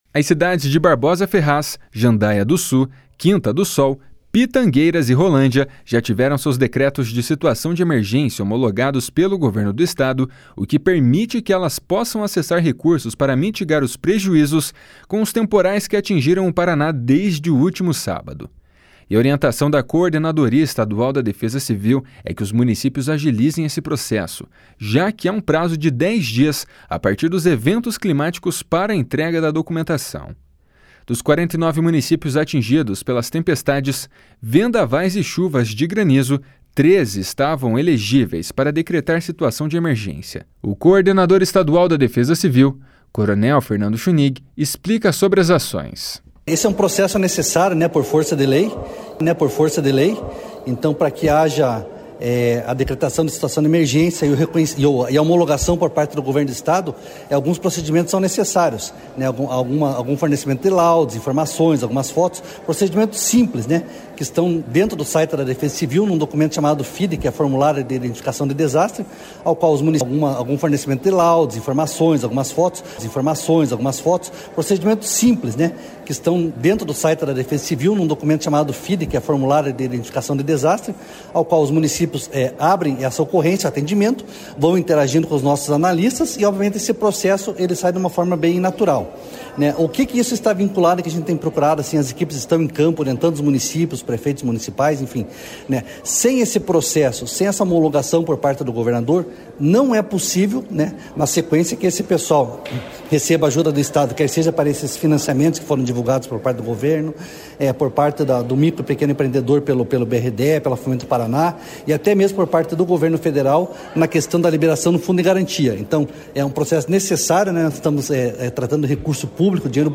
Dos 49 municípios atingidos pelas tempestades, vendavais e chuvas de granizo, 13 estavam elegíveis para decretar situação de emergência. O coordenador estadual da Defesa Civil, coronel Fernando Schünig, explica sobre as ações.